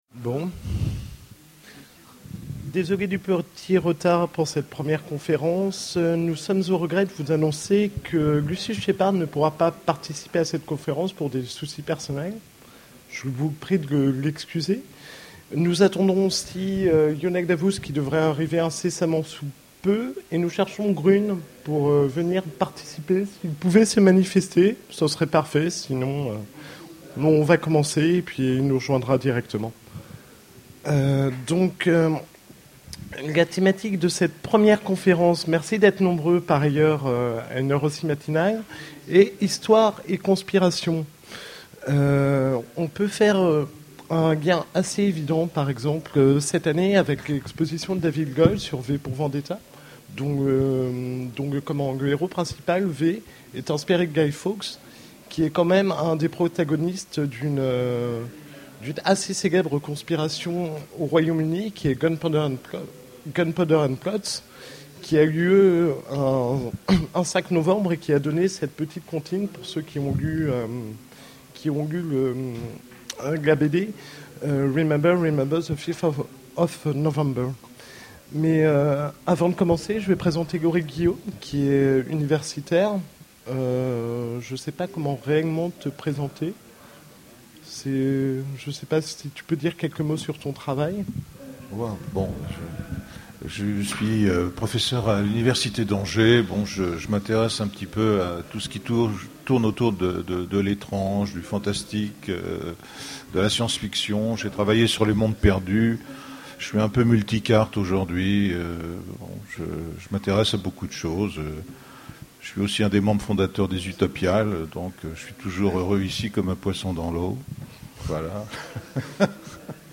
Utopiales 2011 : Conférence Histoire et conspirations
Conférence